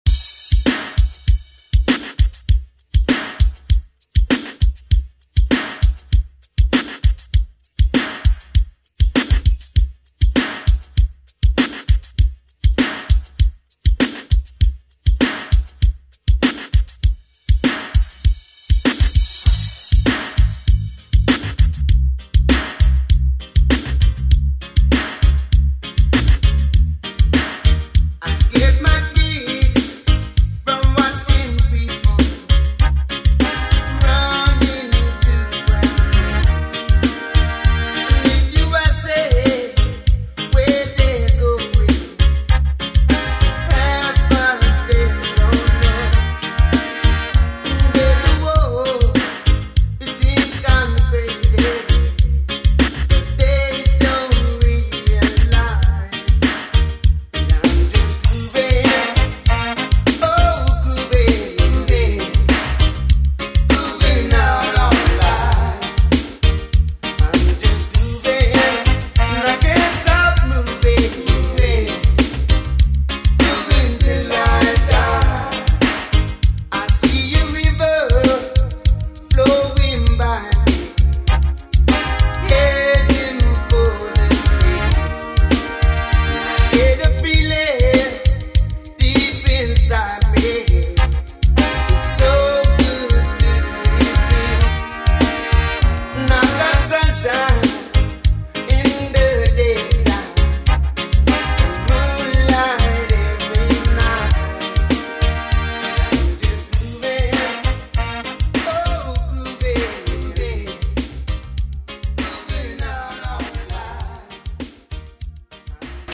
Throwback Pop Hip Hop Rap RnB Music
Extended Intro Outro
100 bpm